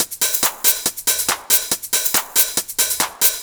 140_HH+shaker_1.wav